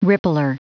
Prononciation du mot rippler en anglais (fichier audio)
Prononciation du mot : rippler